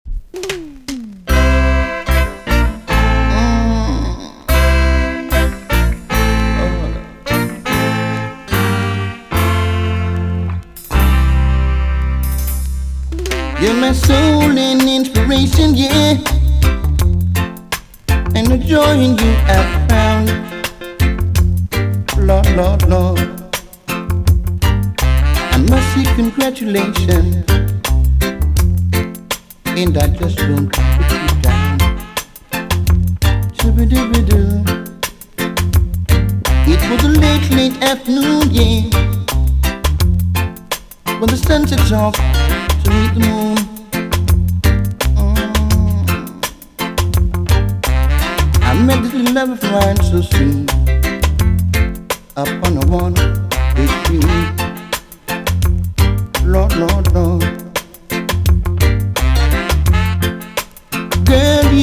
TOP >DISCO45 >80'S 90'S DANCEHALL
EX- 音はキレイです。
1989 , UK , NICE VOCAL TUNE!!